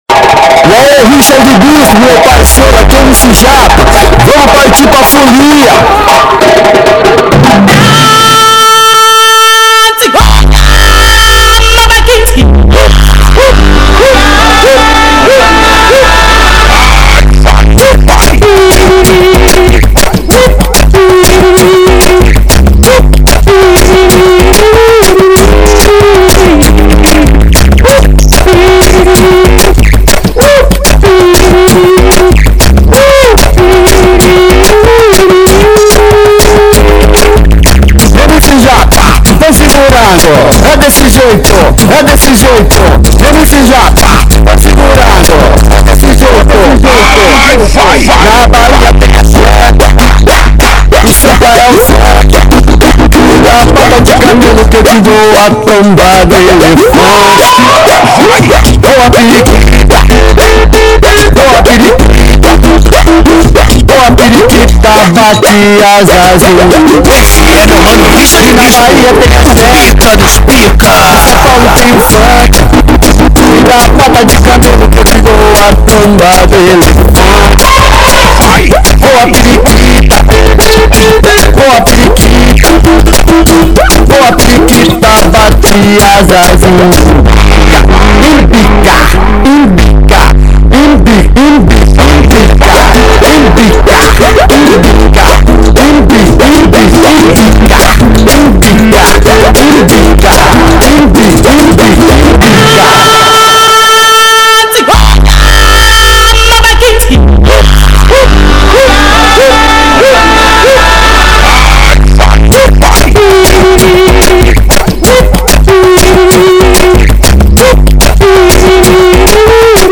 funk.